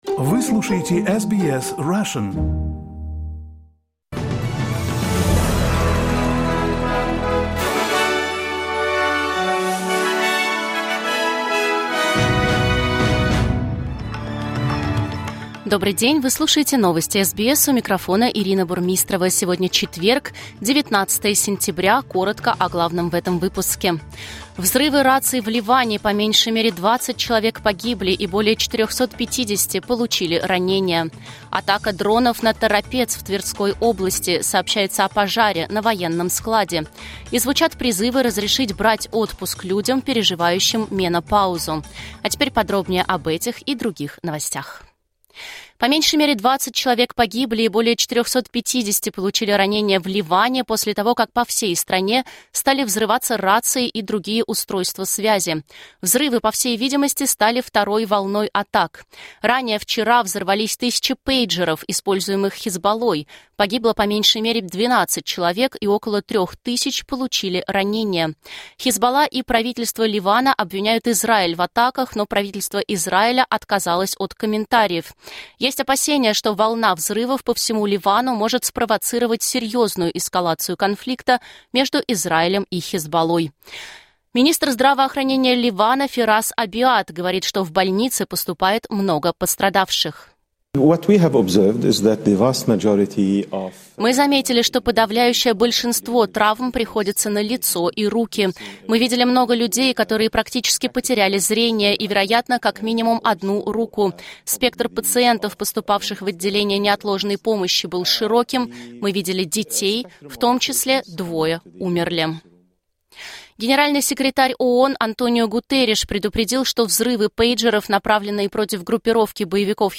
Новости SBS на русском языке — 19.09.2024